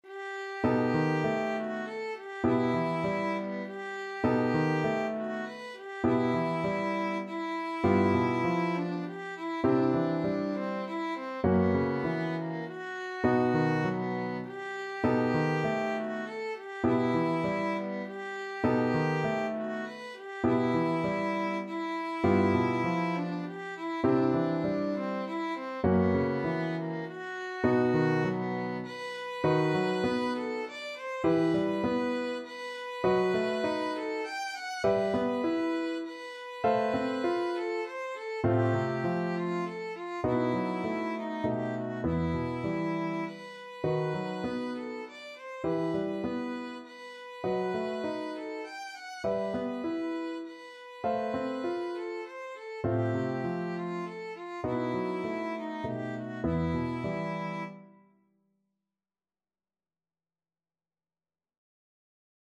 3/4 (View more 3/4 Music)
Etwas bewegt
Classical (View more Classical Violin Music)